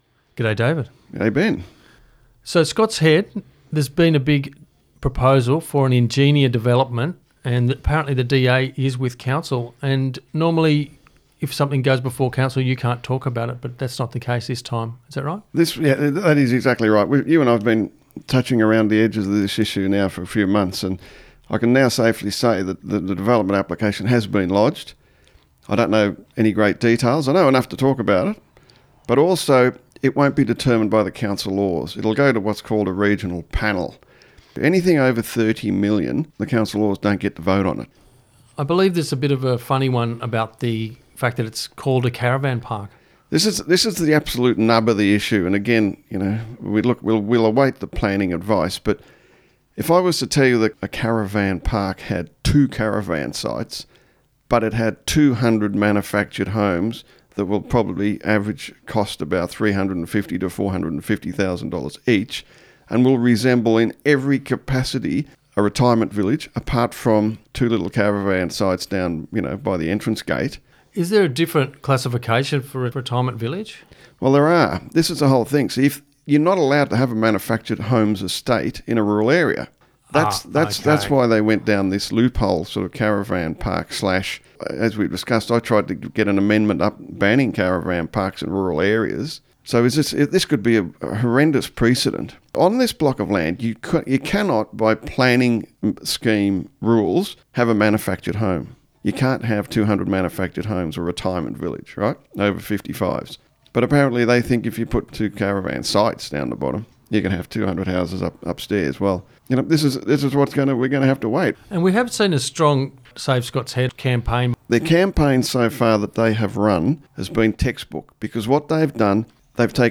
2NVR Radio Interview with Nambucca Councillor David Jones re Ingenia DA.